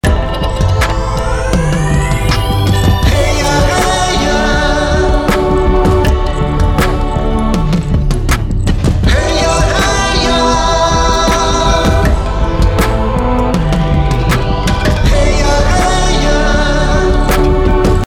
Gegen Ende soll es etwas pompös werden.
Zusammen mit allen anderen Spuren hört es sich der „Schlußrefrain“ nun so an (Ausschnitt) :